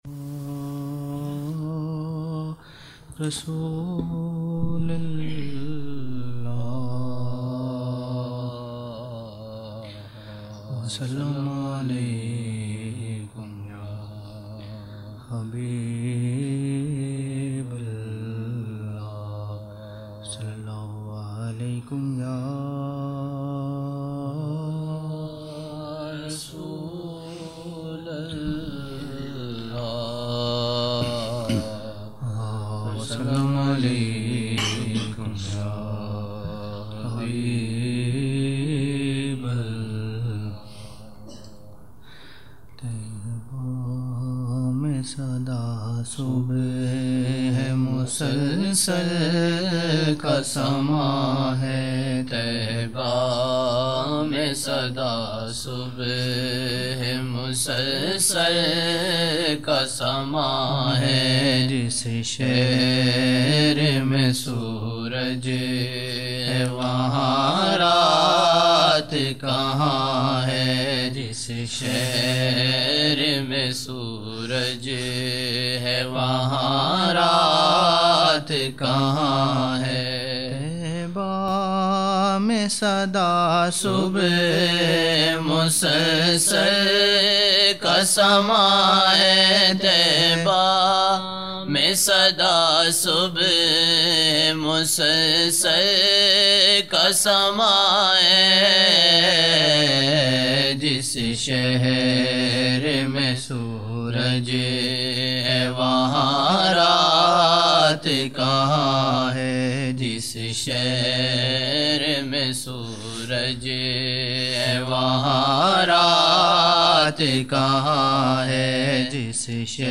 13 November 1999 - Maghrib mehfil (5 Shaban 1420)
مغرب محفل
Naat Shareef